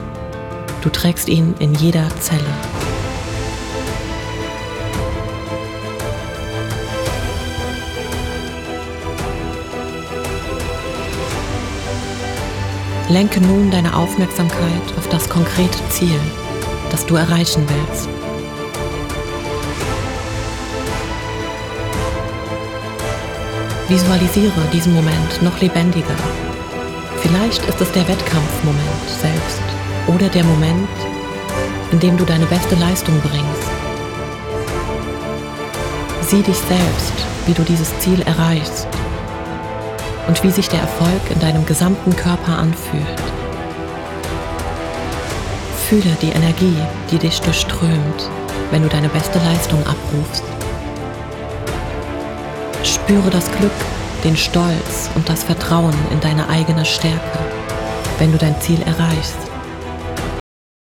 Frequenz: 432 Hz – Fördert Körperbewusstsein und mentale Klarheit.